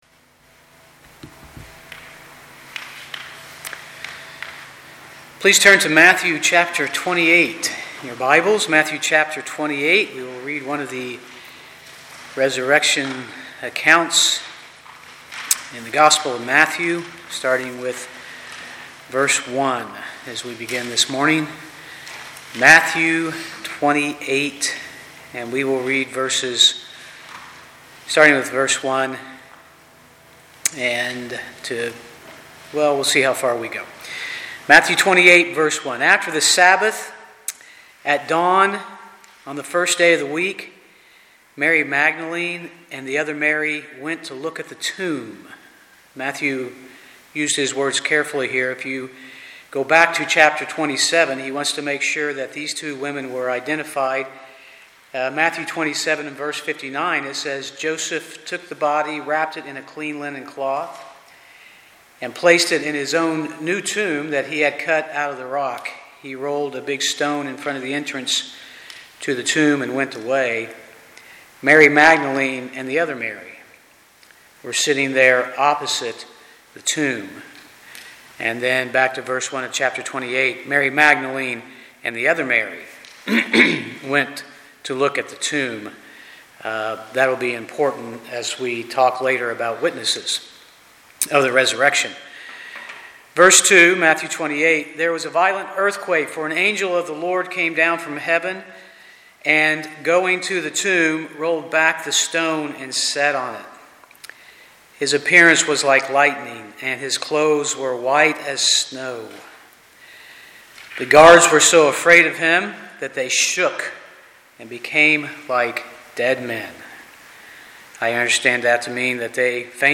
Matthew 28:1-7 Service Type: Sunday morning « Studies in the Book of Revelation #16A